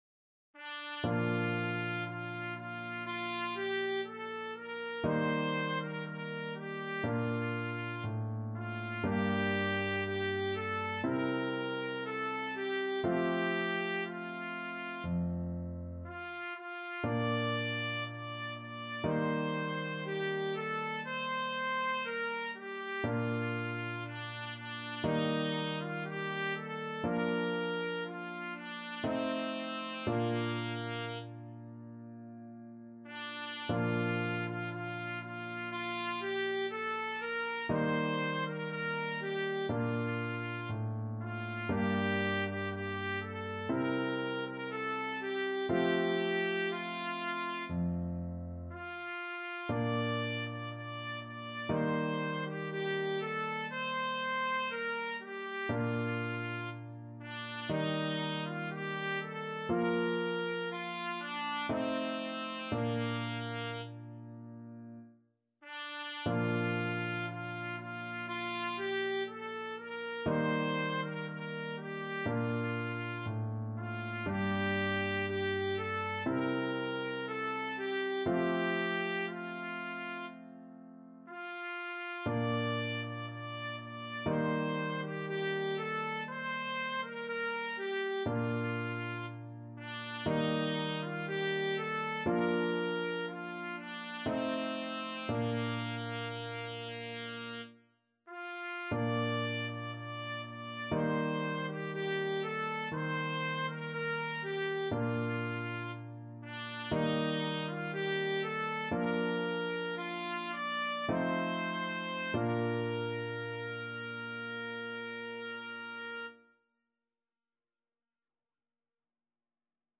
Trumpet
Bb major (Sounding Pitch) C major (Trumpet in Bb) (View more Bb major Music for Trumpet )
4/4 (View more 4/4 Music)
~ = 100 Adagio
Classical (View more Classical Trumpet Music)